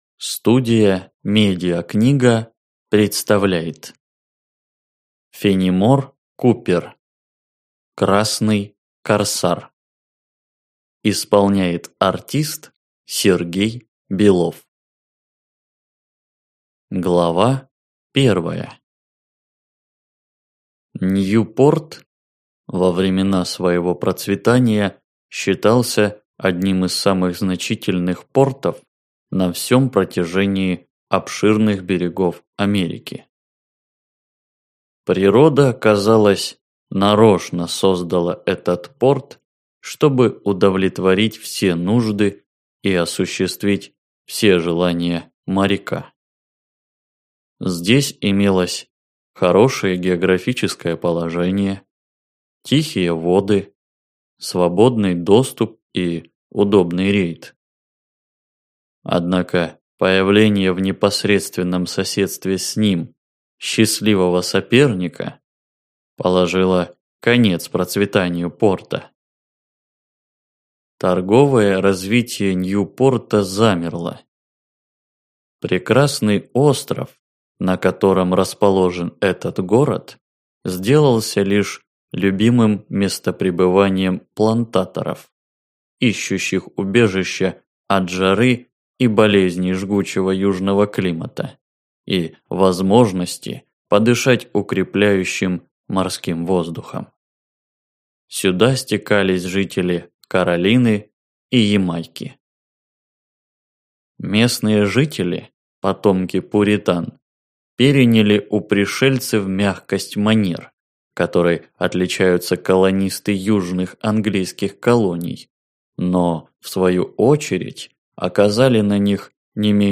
Аудиокнига Красный корсар - купить, скачать и слушать онлайн | КнигоПоиск